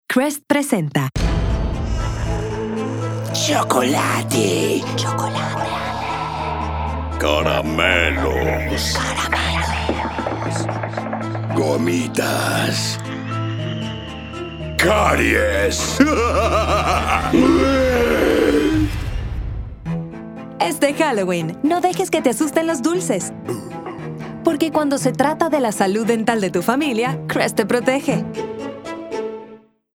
I had an absolute blast bringing these radio spots to life, and I hope you enjoy listening to them as much as I enjoyed creating them!